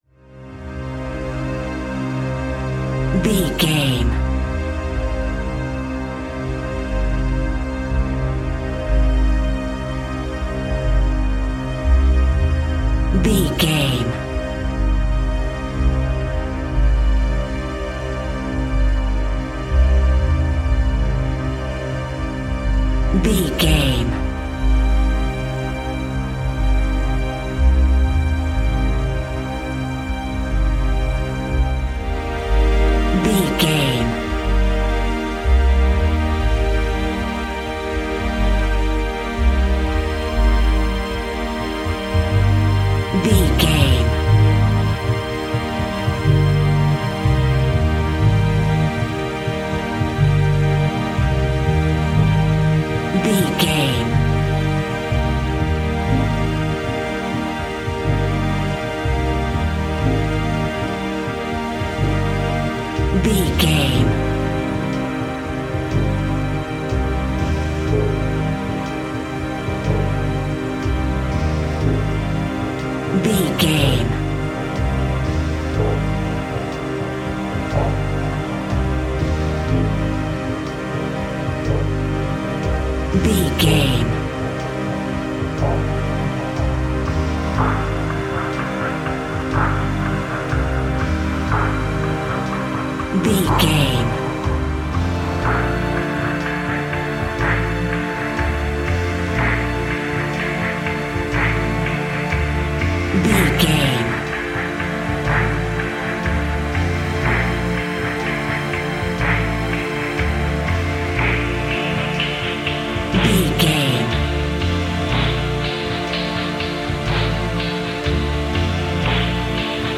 Aeolian/Minor
ominous
haunting
eerie
synthesizer
Horror Pads
horror piano
Horror Synths